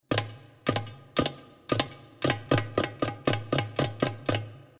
doigts.mp3